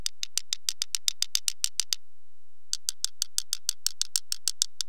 I made wrench test, knocing the block with wrench and record the signal with laptop by this simple circuit:
First hit the block at the bottom where the knock sensor is but opposite side, then at the middle of the bore.
wrenchtest.wav